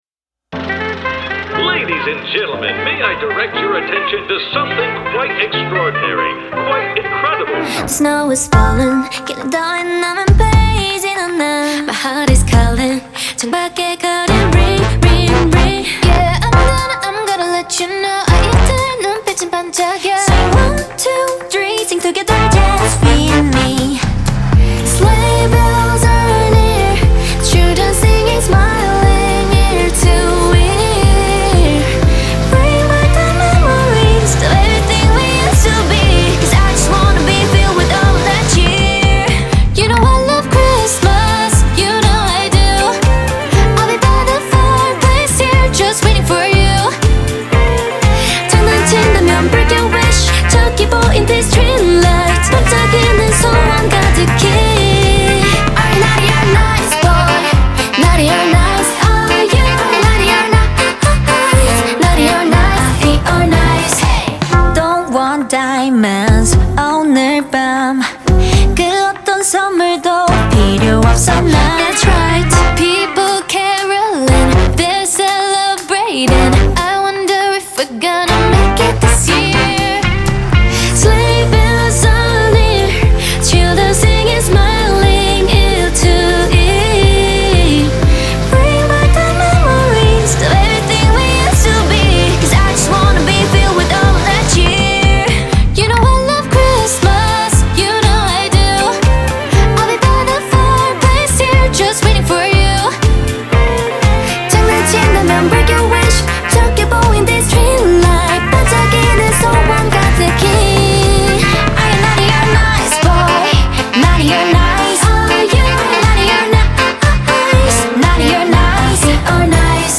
• Жанр: K-pop